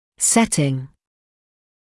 [‘setɪŋ][‘сэтин]окружающая обстановка; регулирование; затвердевание